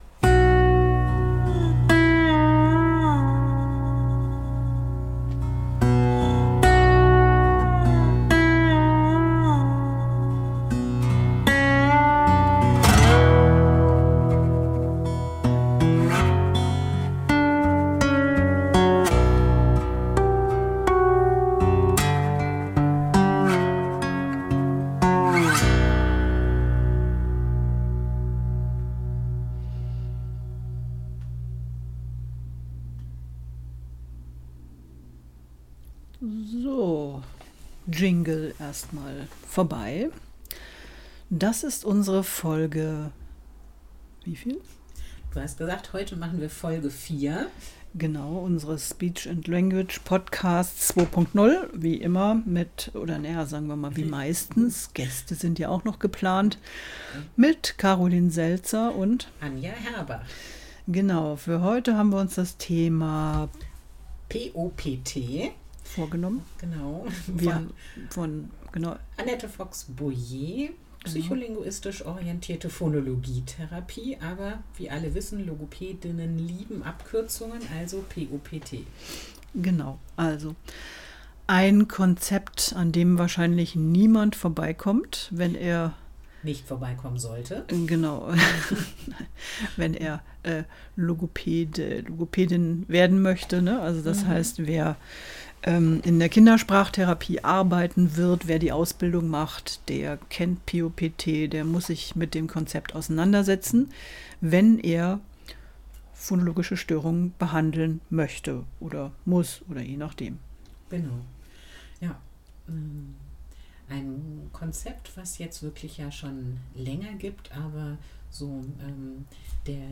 plaudern aus dem therapeutischen Nähkästchen